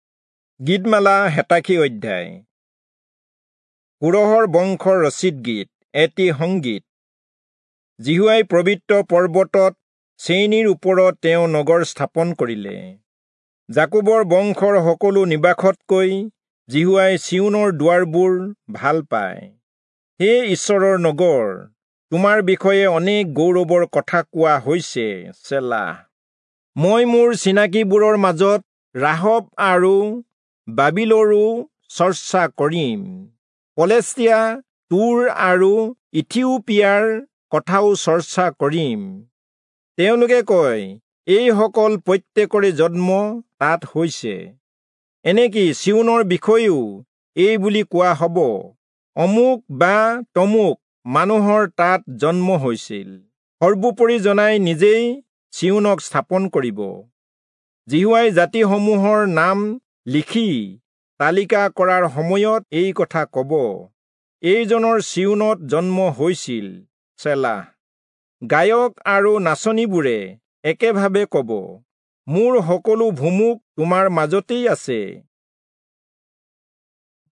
Assamese Audio Bible - Psalms 98 in Tev bible version